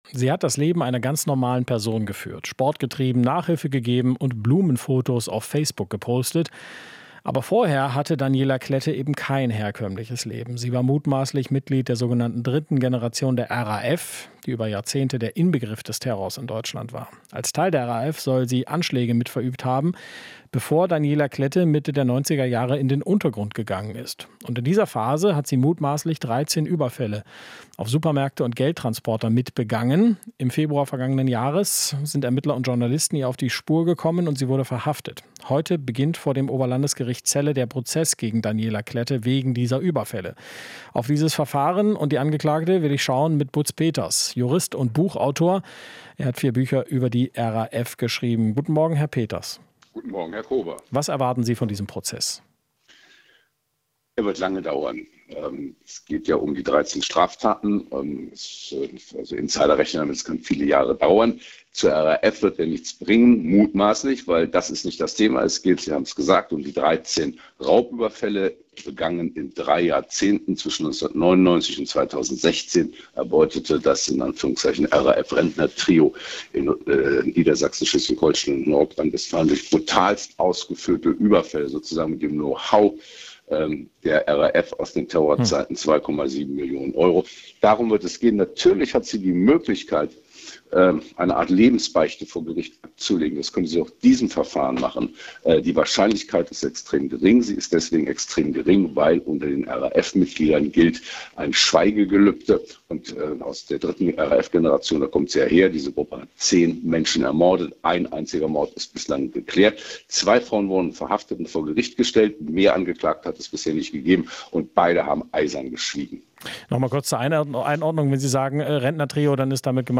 Interview - RAF-Experte: Wie konnte Klette 30 Jahre unentdeckt bleiben?